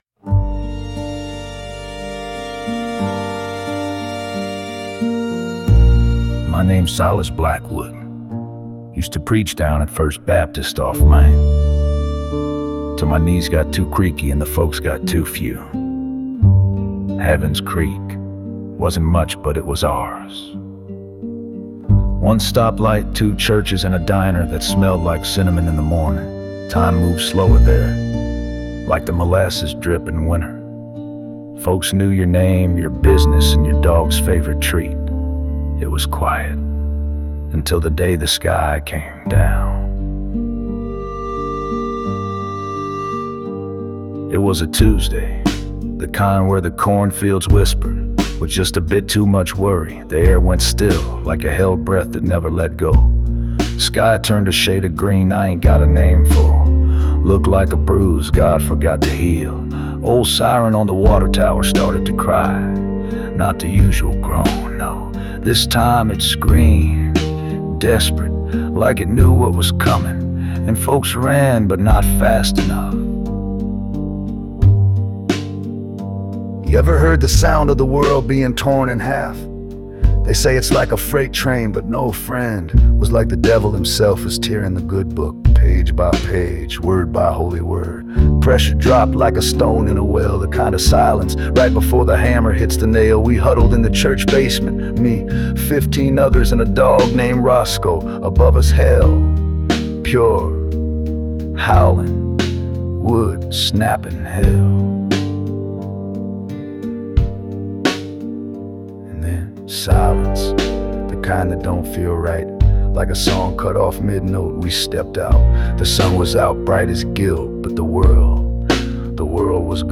Here is a song I put together about a tornado hitting the small town of Haven's Creek. It is a sad, spoken-word song that helps us understand the tragedy of severe weather events.